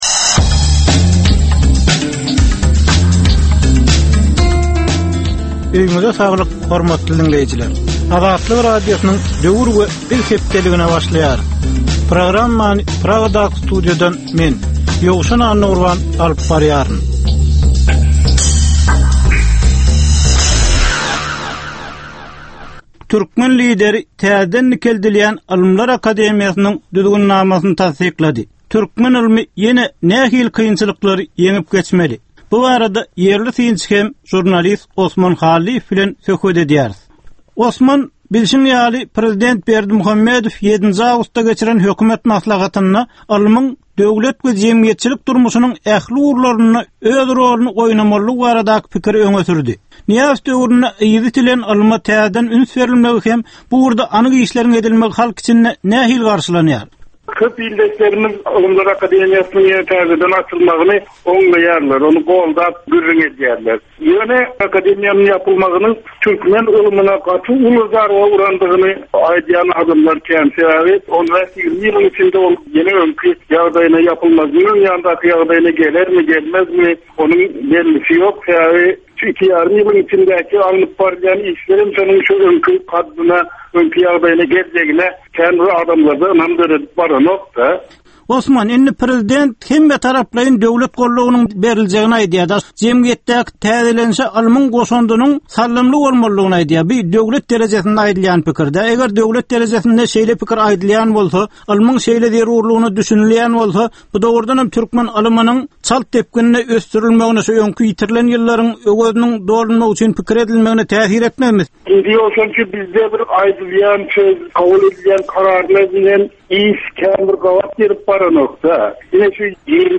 Türkmen jemgyýetindäki döwrün meseleleri. Döwrün anyk bir meselesi barada 10 minutlyk ýörite syn-geplesik. Bu geplesikde dinleýjiler, synçylar we bilermenler döwrün anyk bir meselesi barada pikir öwürýärler, öz garaýyslaryny we tekliplerini orta atýarlar.